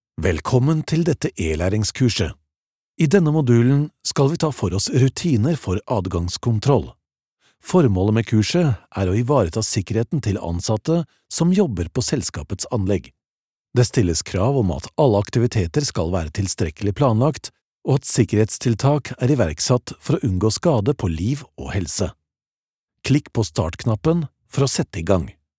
E-Learning
Junger Erwachsener
Im mittleren Alter
WarmAutorisierendGlaubhaftMächtig